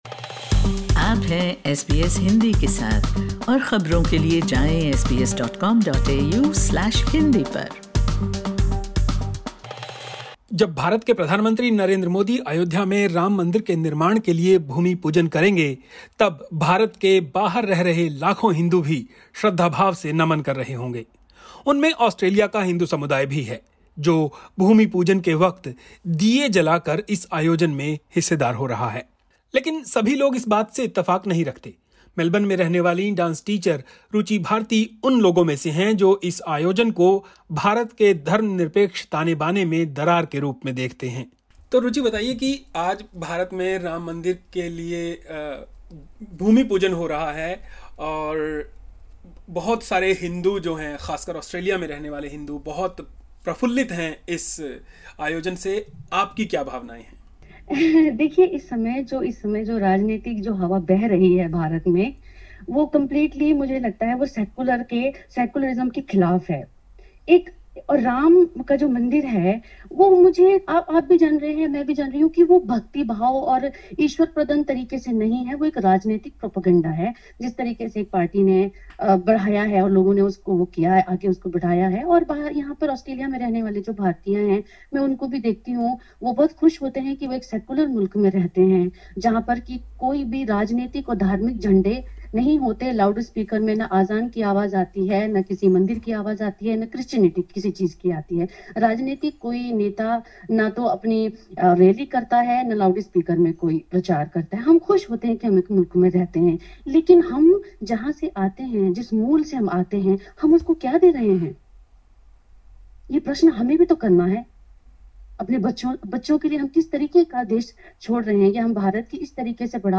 यह बातचीत...